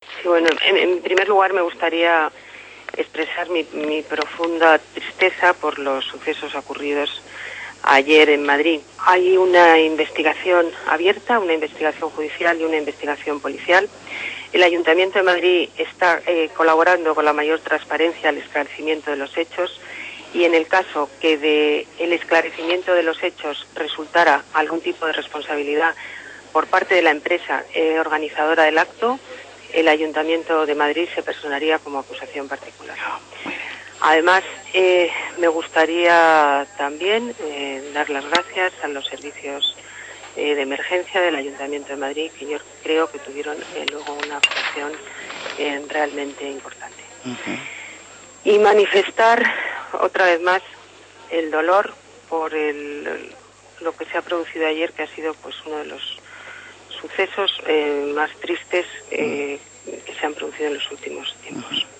Así lo ha anunciado la alcaldesa Ana Botella en declaraciones hechas a la Agencia EFE